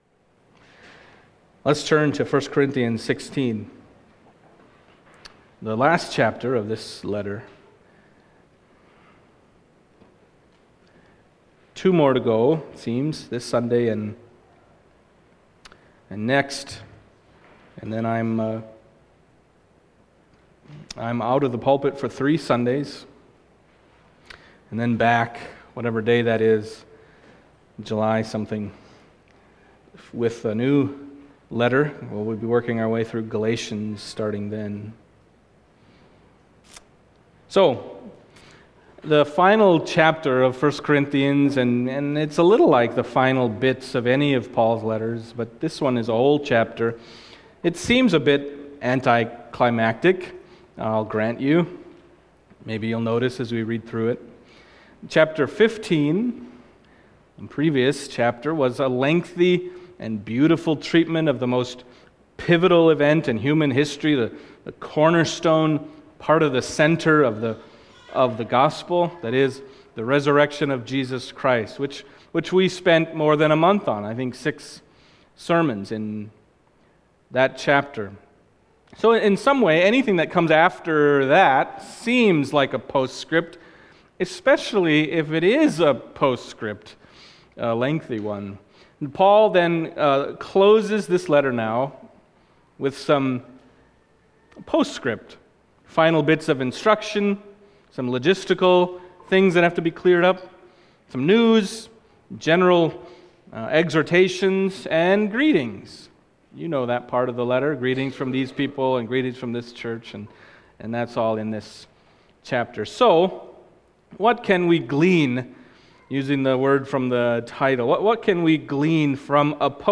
Passage: 1 Corinthians 16:1-9 Service Type: Sunday Morning